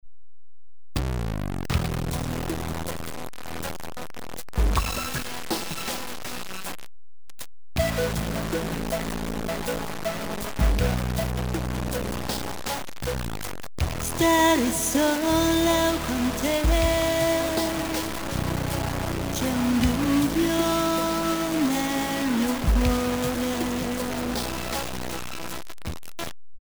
Bruit de quantification
Cette perte est due à la quantification comme l'illustre la figure ci-dessous : La dégradation du signal s'apparente à une forme de distorsion, cependant elle est perçue (dans le domaine audio) comme une forme de bruit.